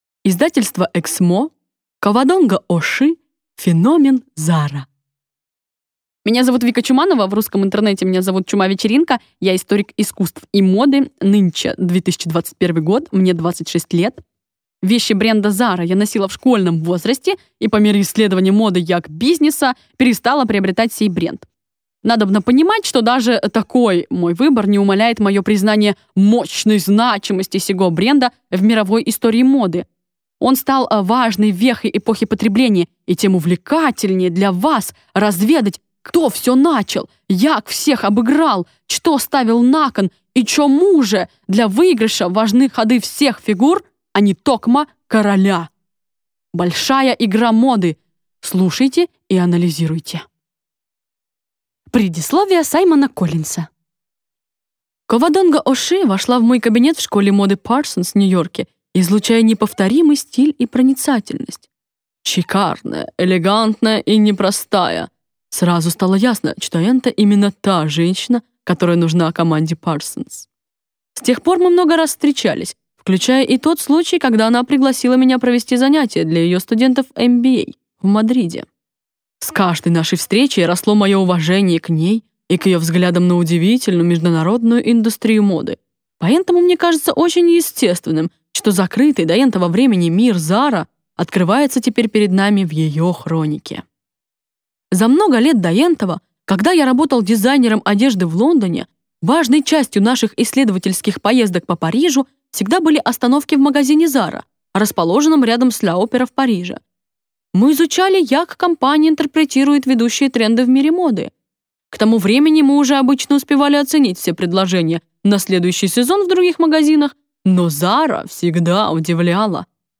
Аудиокнига Феномен ZARA | Библиотека аудиокниг